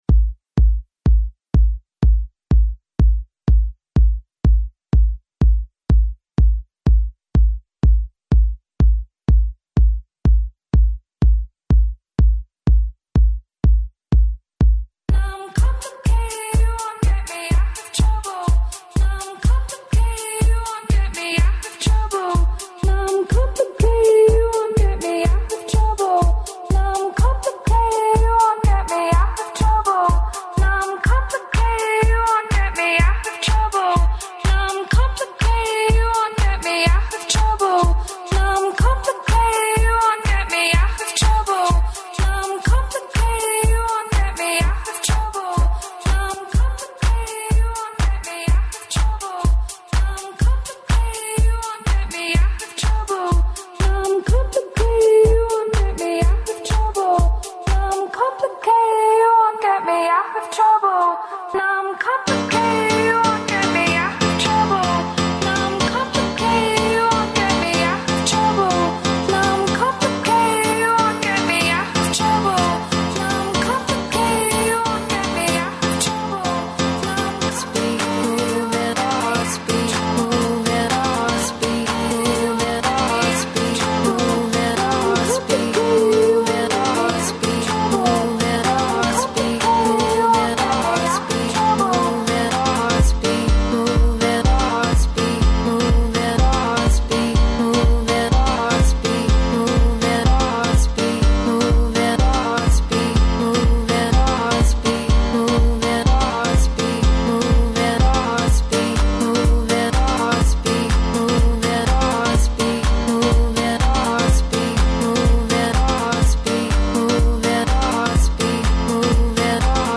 Девети, музички фестивал Love Fest, почиње вечерас у Врњачкој Бањи, одакле ће се реализовати специјално, двочасовно издање емисије.